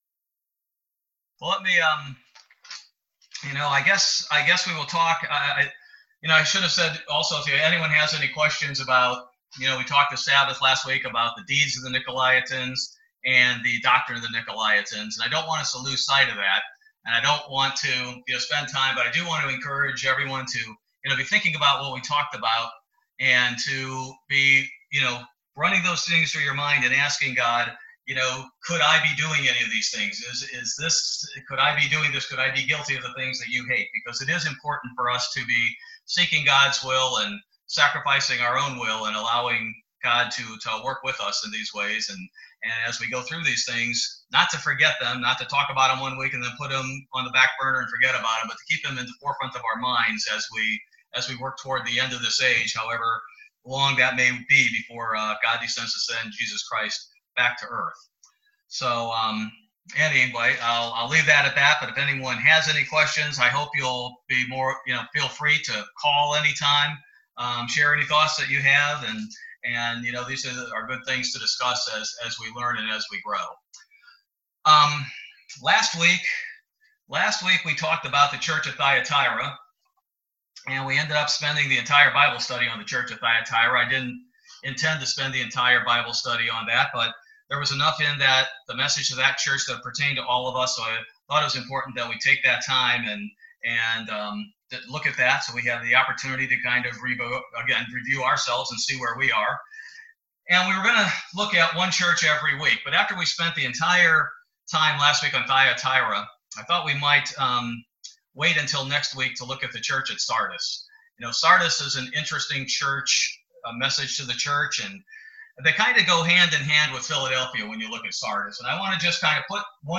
Bible Study - July 29, 2020